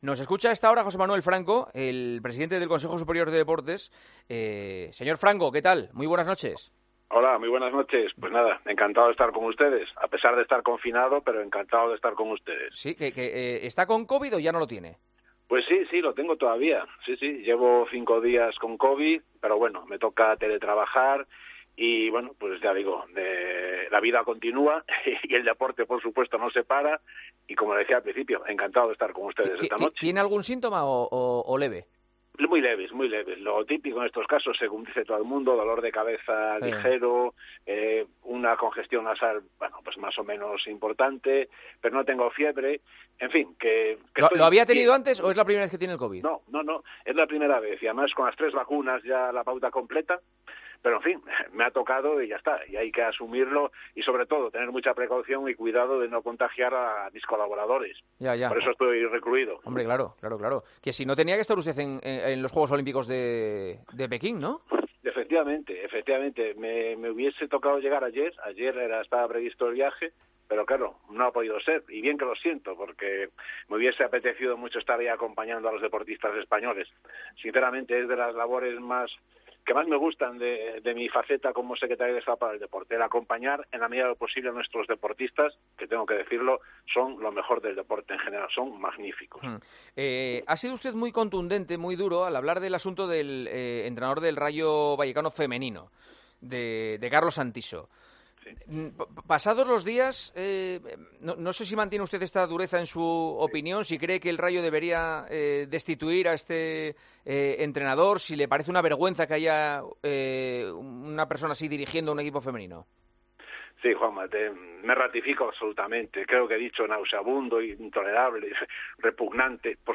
AUDIO - ENTREVISTA A JOSÉ MANUEL FRANCO, EN EL PARTIDAZO DE COPE